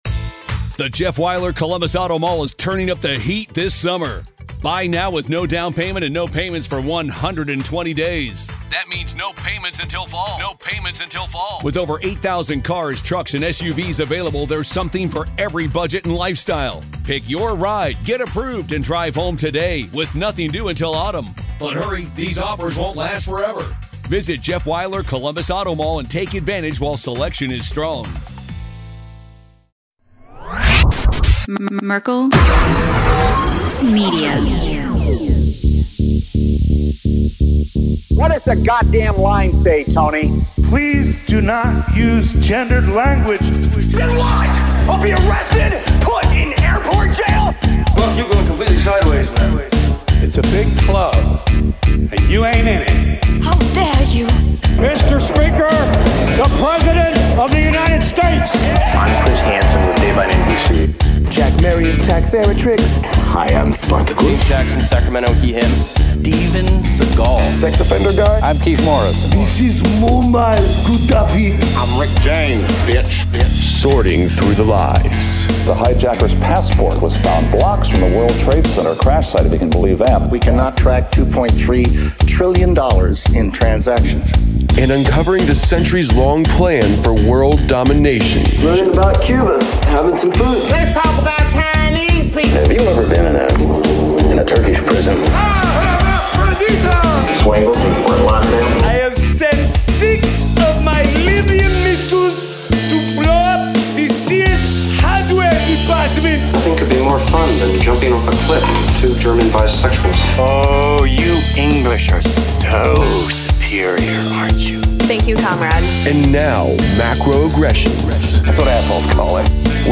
UFO Roundtable Swapcast